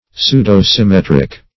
Search Result for " pseudo-symmetric" : The Collaborative International Dictionary of English v.0.48: Pseudo-symmetric \Pseu`do-sym*met"ric\, a. (Crystallog.) Exhibiting pseudo-symmetry.